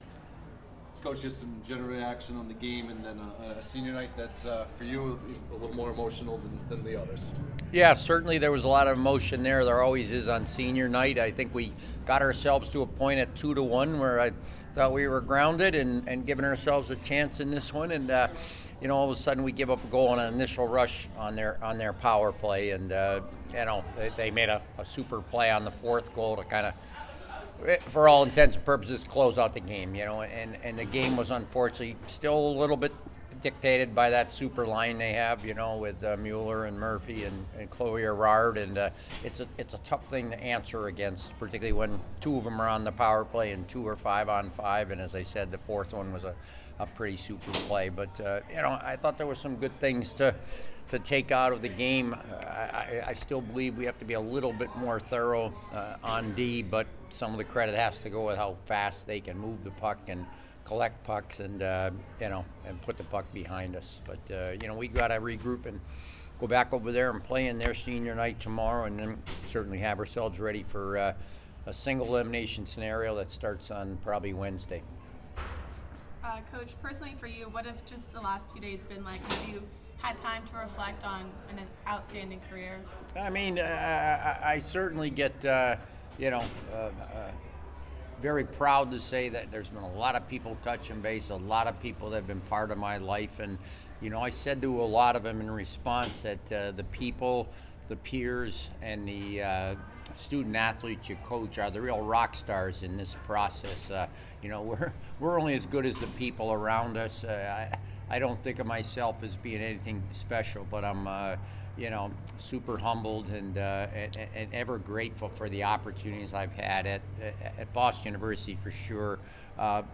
Women's Ice Hockey / Northeastern Postgame Interview (2-17-23)